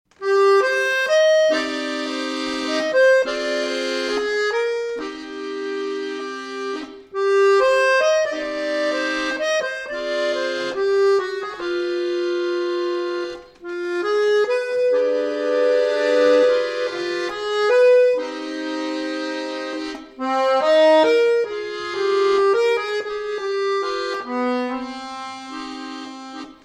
• Качество: 320, Stereo
мелодичные
без слов
Cover
аккордеон
инструментальные